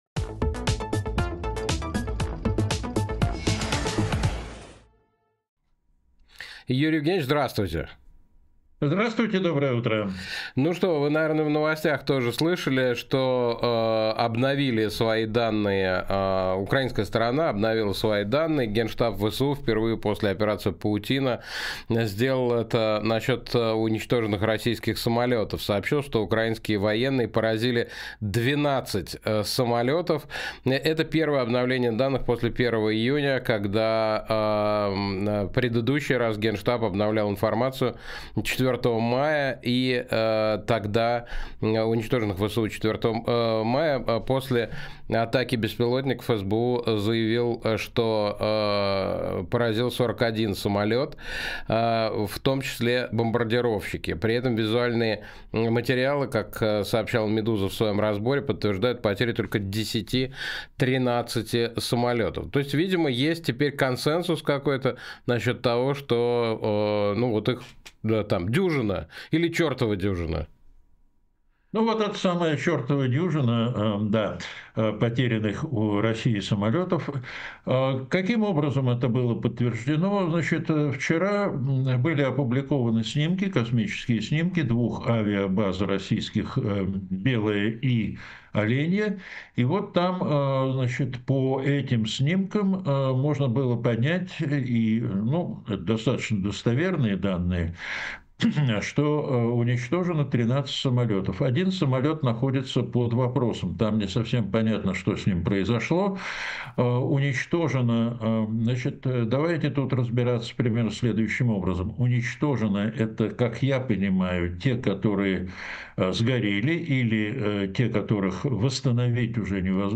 Фрагмент эфира от 03.06